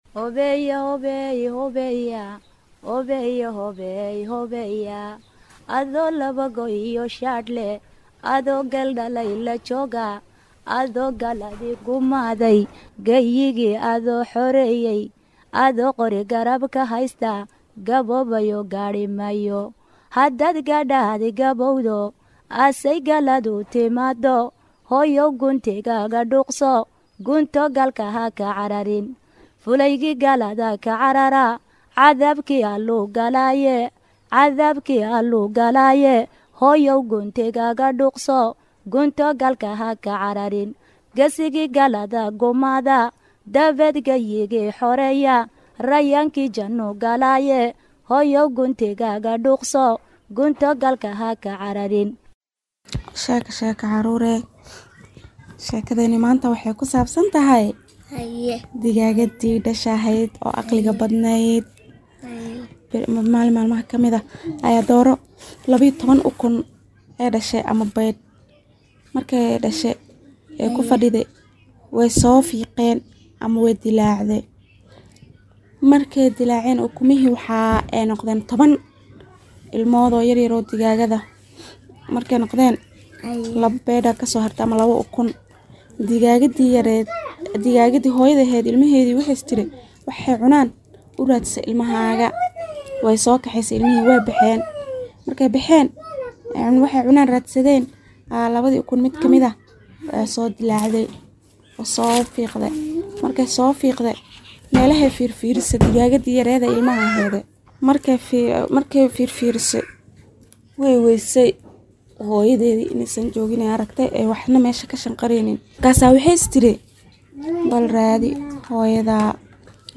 Barnaamijka Tarbiyada Caruurta ee Jimco weliba ka baxa warbaahinta Islaamiga ah ee Al-Furqaan, waa barnaamij tarbiyo iyo barbaarin oo ku socda caruurta iyo waalidiintooda, waxaana xubnaha ku baxa kamid ah xubin loogu magacdaray Sheeko-Xariir, oo ay soo jeedinayaan hooyooyinka Soomaaliyeed.